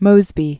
(mōzbē), John Singleton 1833-1916.